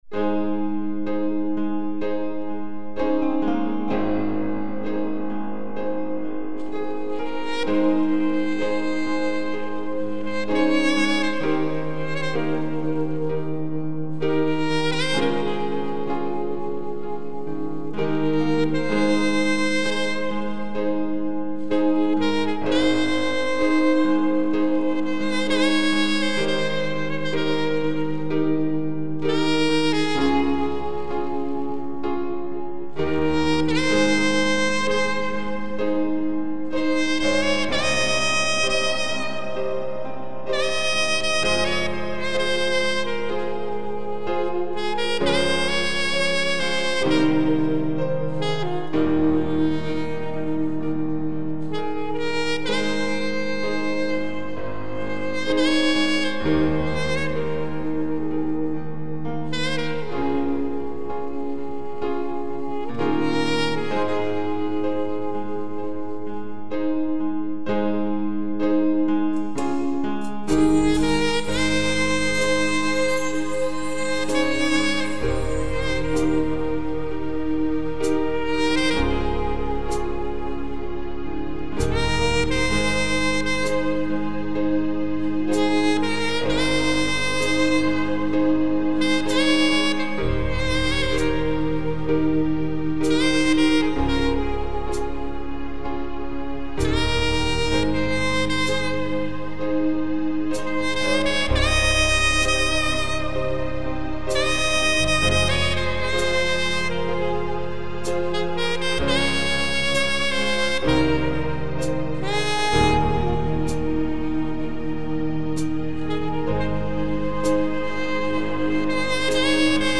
Saxophone Instrumentals